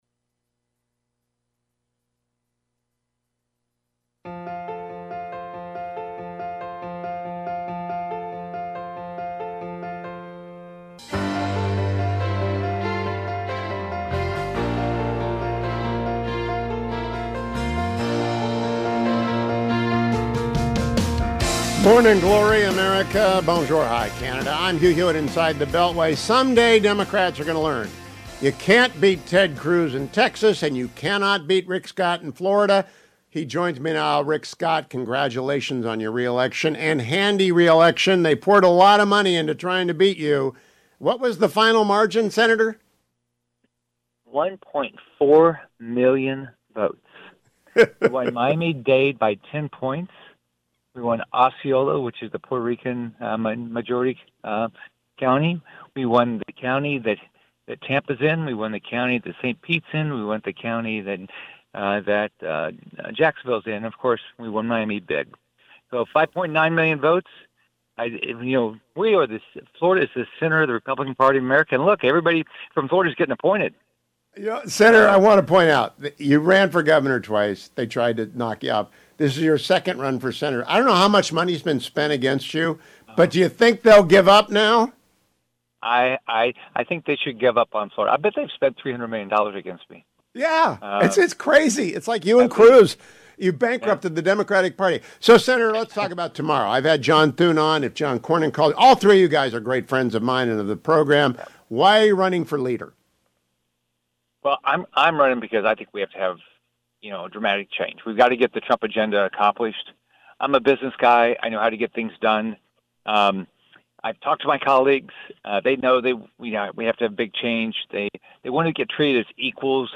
Senator Rick Scott of Florida joined me this morning.